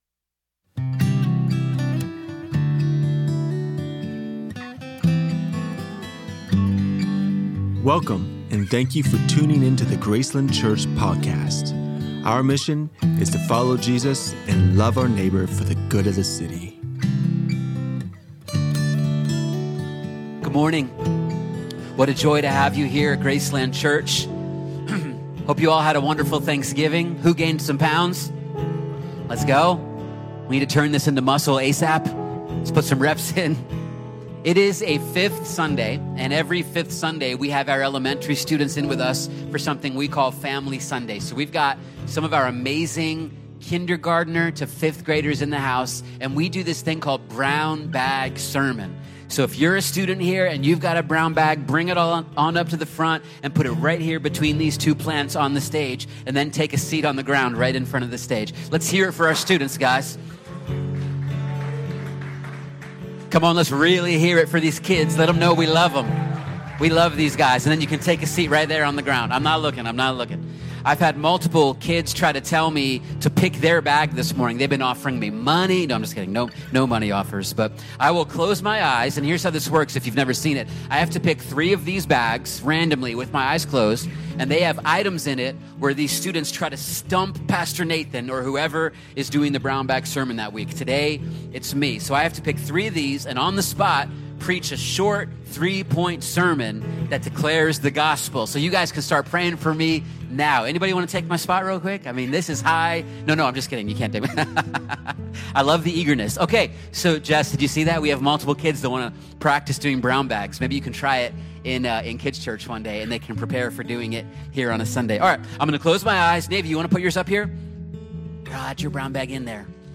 Gratitude by Faith – Staff Panel